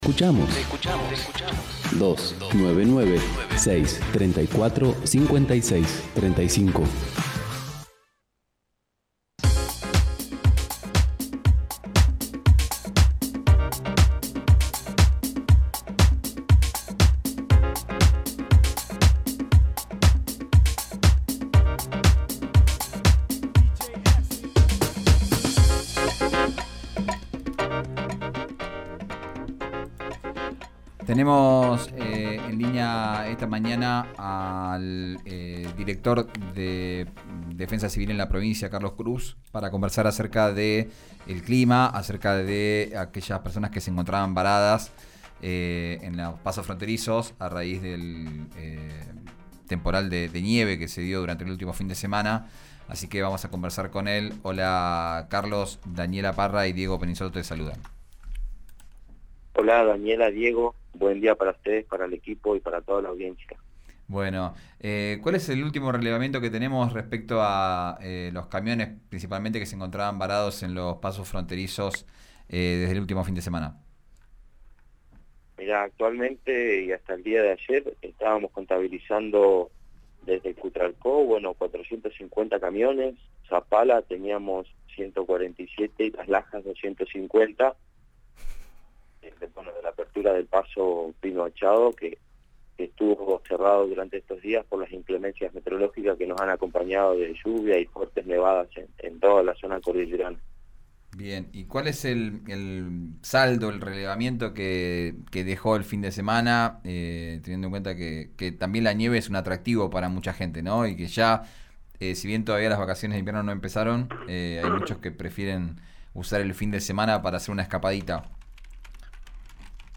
Escuchá a Carlos Cruz Aravena, director de Defensa Civil de Neuquén, en RÍO NEGRO RADIO: